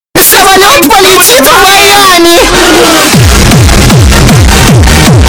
• Качество: 128, Stereo
громкие
веселые
из мультсериала
Осторожно, мощный бас!